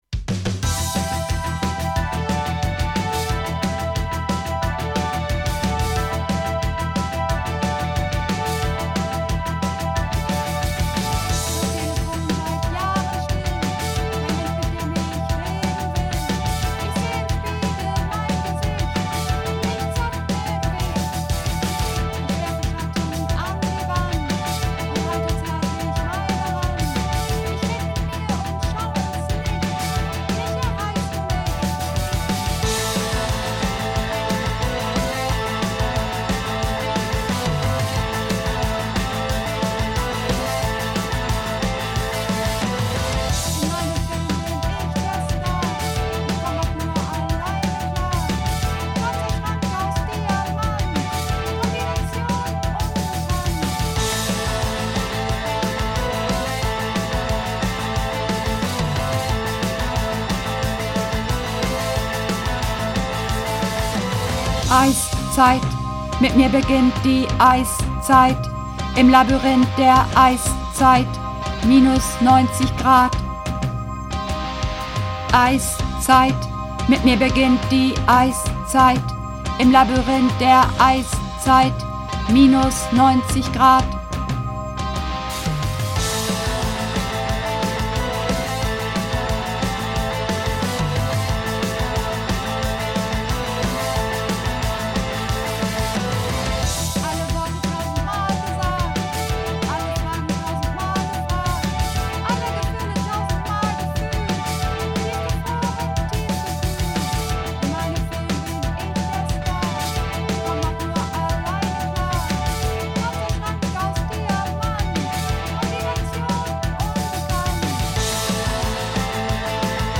Übungsaufnahmen - Eiszeit
Eiszeit (Alt)
Eiszeit__1_Alt.mp3